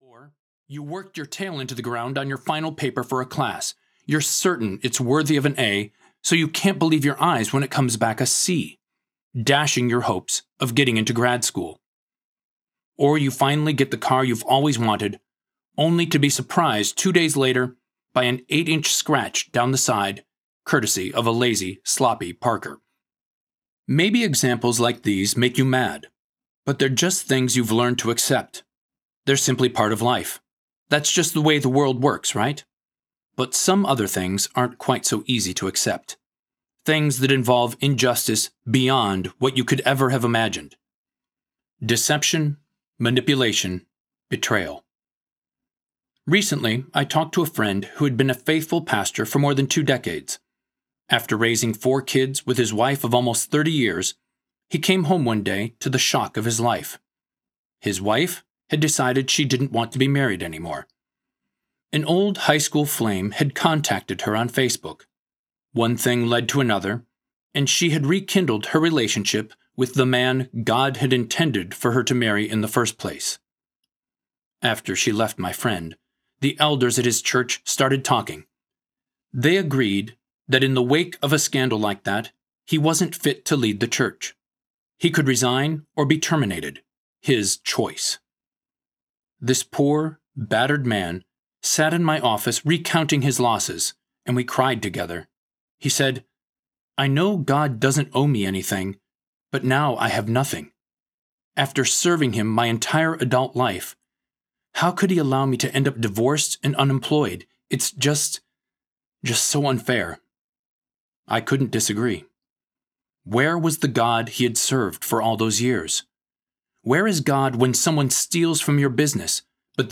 Hope in the Dark Audiobook
3.52 Hrs. – Unabridged